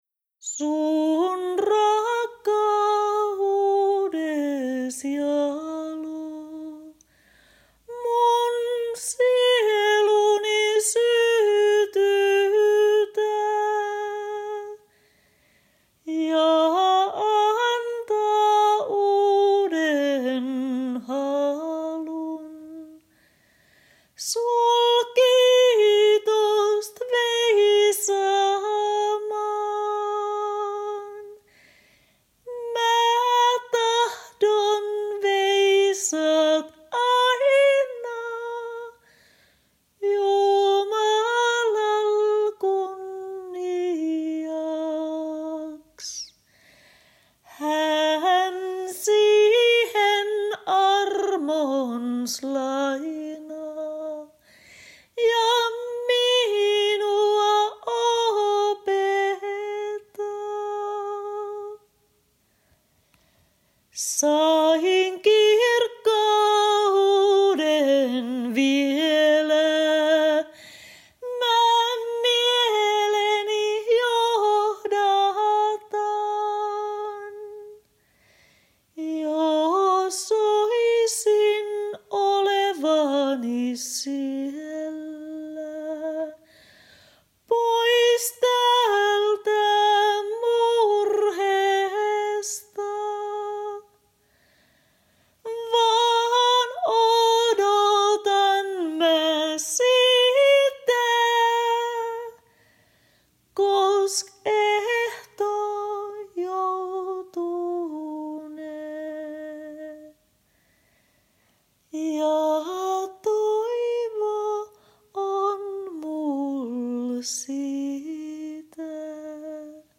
Sävelmä trad.